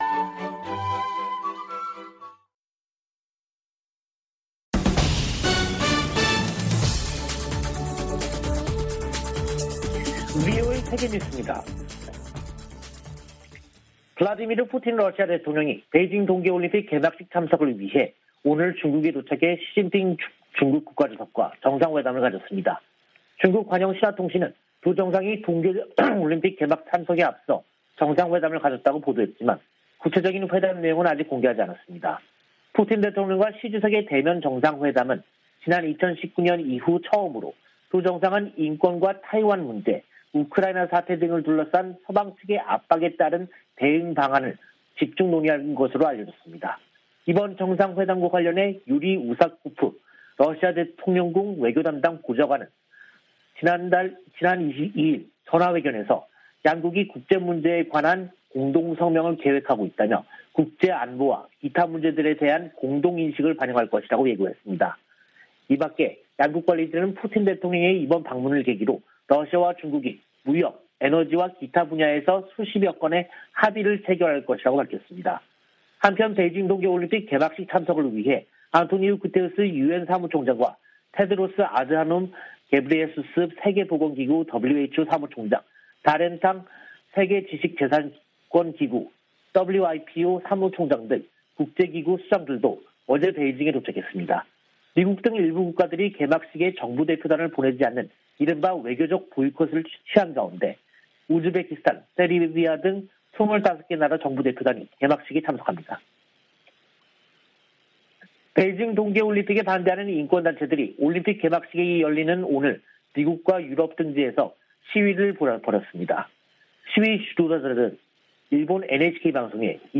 VOA 한국어 간판 뉴스 프로그램 '뉴스 투데이', 2022년 2월 4일 2부 방송입니다. 미 국무부는 북한의 탄도미사일 발사에 대응해 동맹과 파트너는 물론, 유엔과 협력하고 있다고 밝혔습니다. 미군 당국이 일본·호주와 실시 중인 연합 공중훈련의 목적을 설명하면서 북한을 거론했습니다.